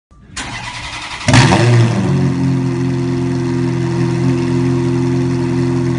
ferrari-f430-egzoz-sesi.mp3